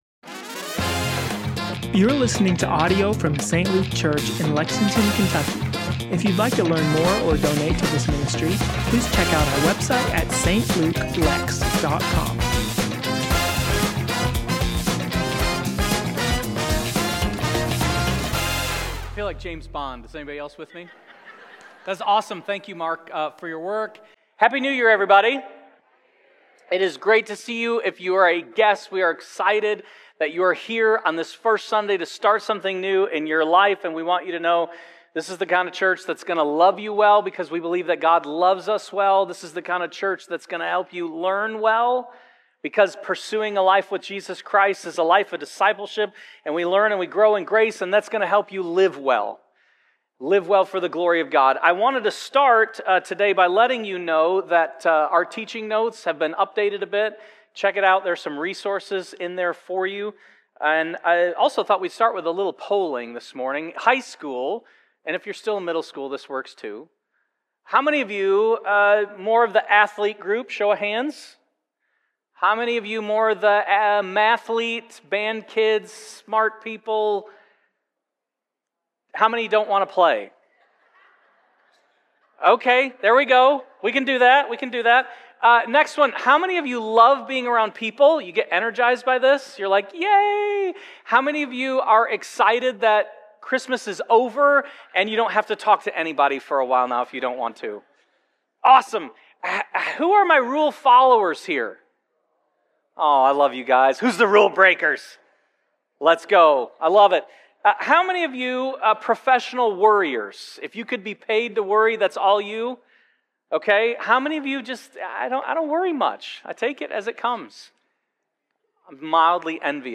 1-4-26-St-Luke-Sermon-Podcast.mp3